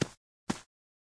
AV_footstep_walkloop.ogg